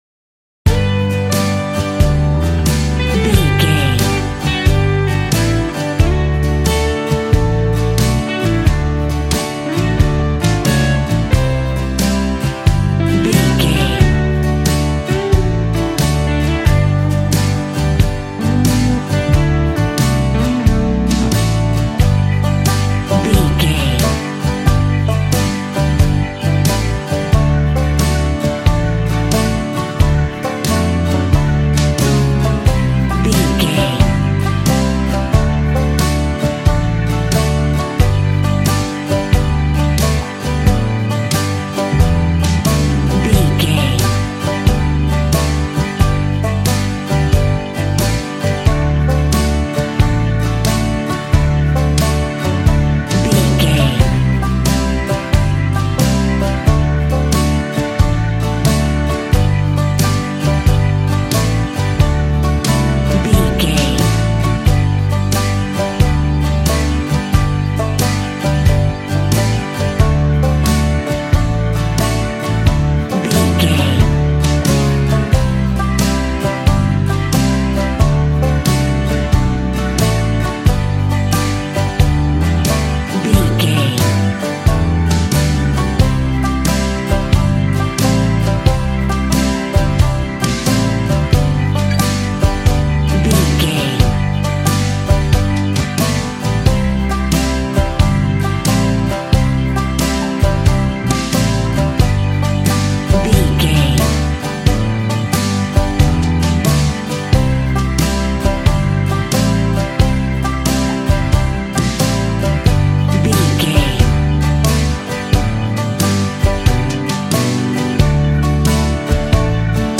A romantic piece of country waltz music.
Ionian/Major
Fast
fun
bouncy
double bass
drums
acoustic guitar